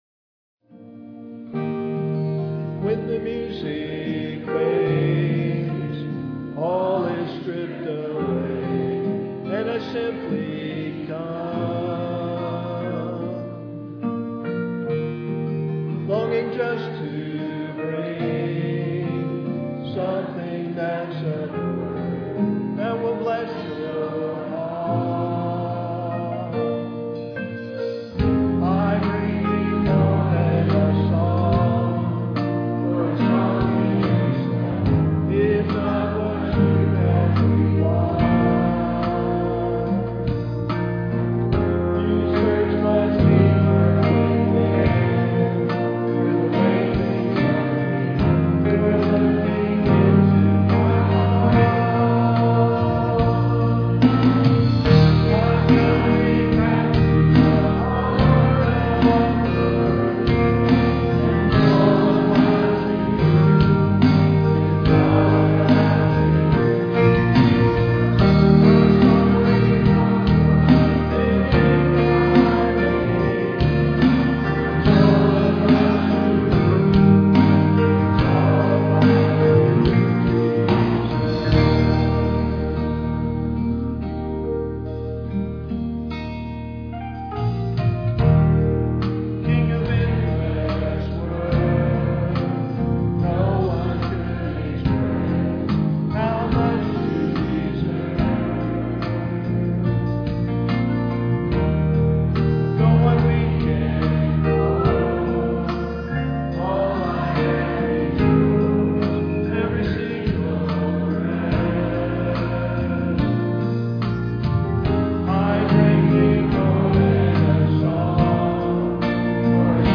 duet with guitar
guitar and vocal
Christian Life Assembly Quartet
piano and vocal
reading and vocal solo